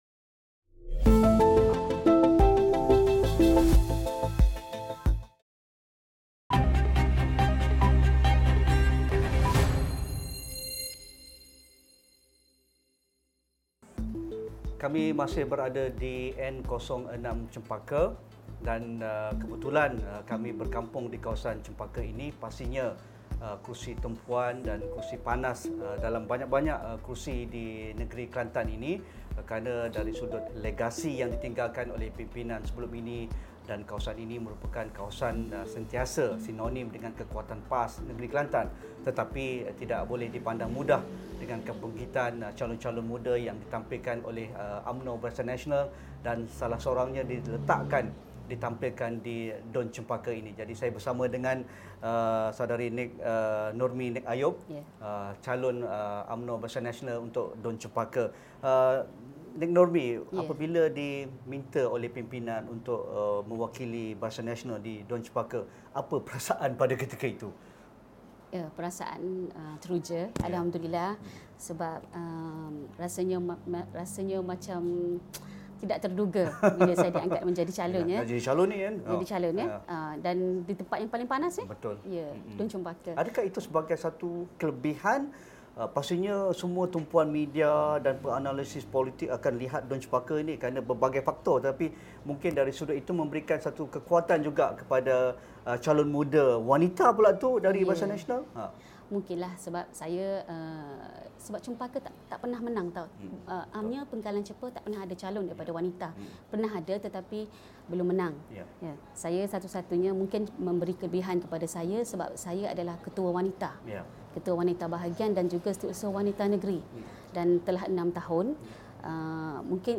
Isu pemilihan calon, legasi perjuangan Nik Aziz dan pembangunan setempat antara isu tumpuan kerusi panas DUN N.06 Chempaka. Temu bual bersama penganalisis dan calon di Dataran Sireh, Kota Bharu 8.30 malam ini.